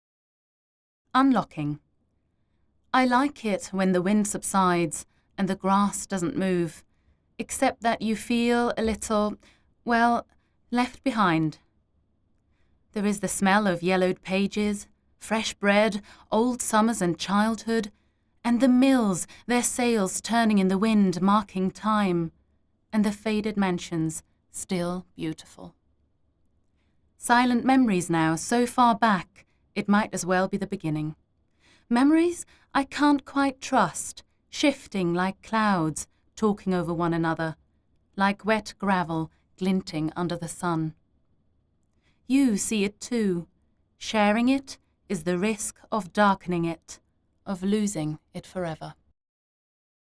Poetry
Recorded at DoubleDouble Creative & Productions, Hong Kong